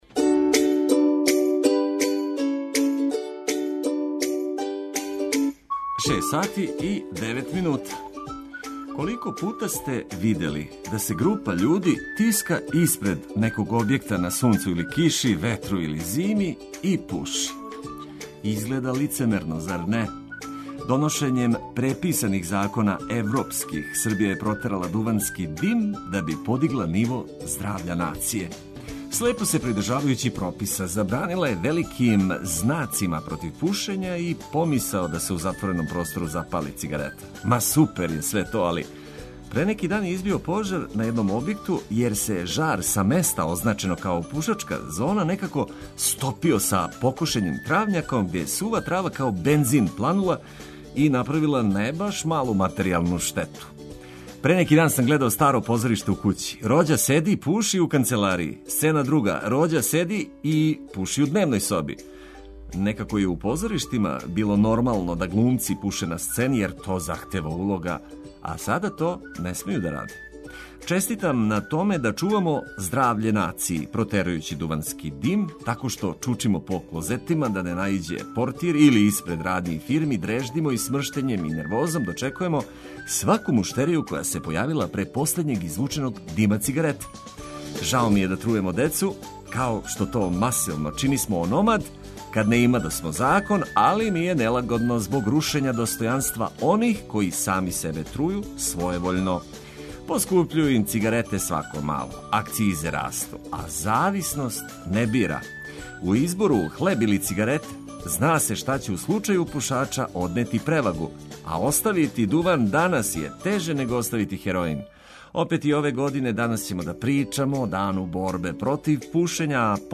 Уредник и водитељ: